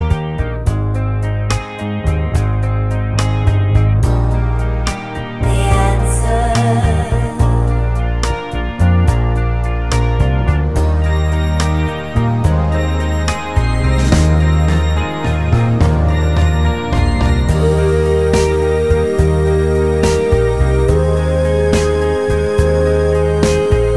Country (Female)